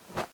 Throwing.ogg